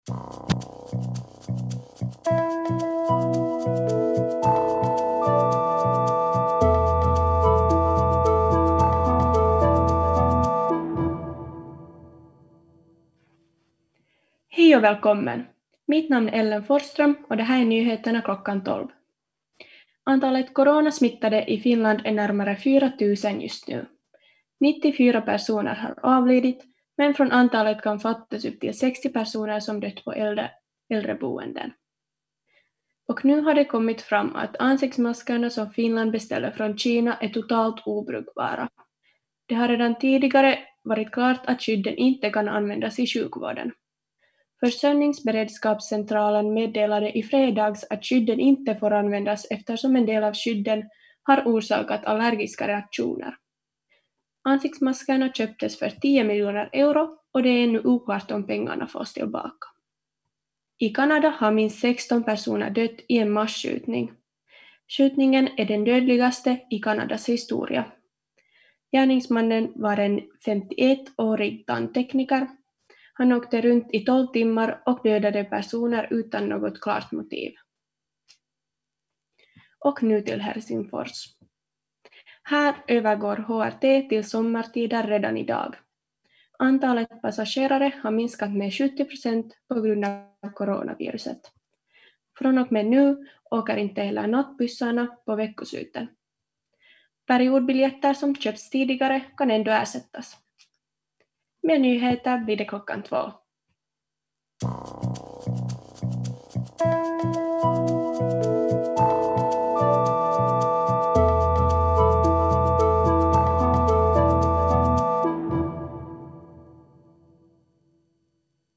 Nyhetstelegram 20.4 kl.12